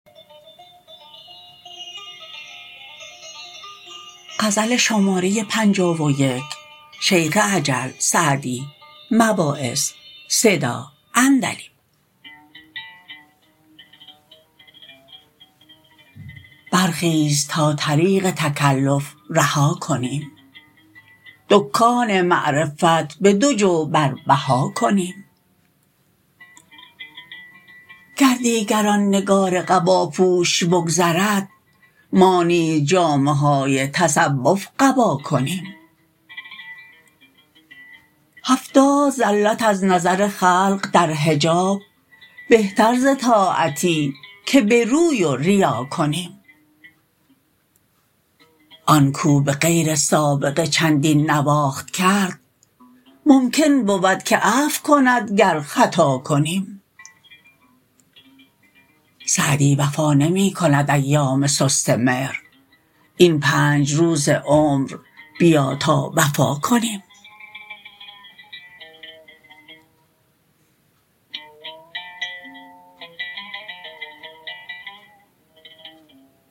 سعدی » مواعظ » غزلیات » غزل شمارهٔ ۵۱ با خوانش